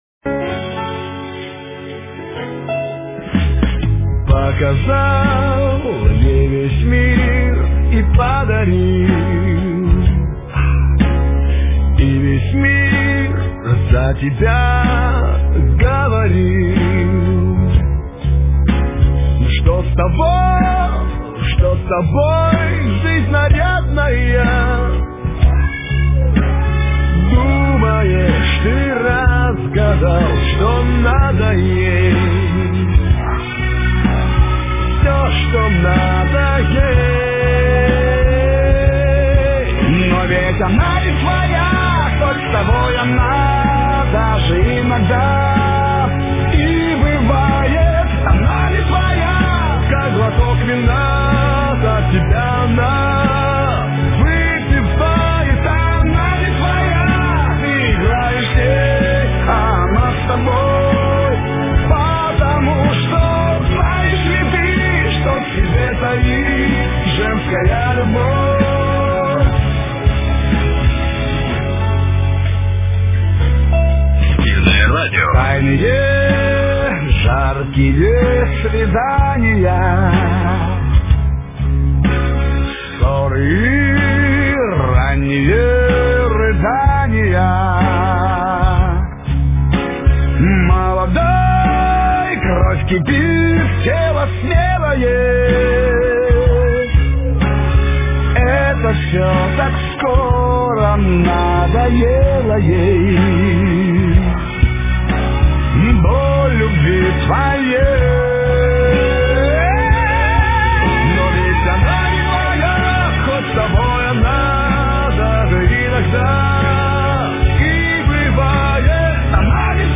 Эстрада, шансон